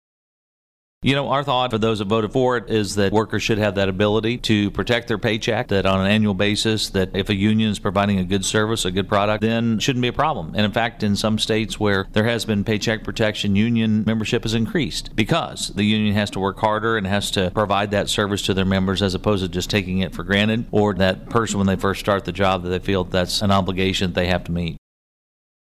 Senator Pearce adds House Bill 1891, which would create new provisions of law relating to labor organizations, which the governor’s decision.